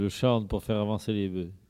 Il chante pour faire avancer les bœufs
Vendée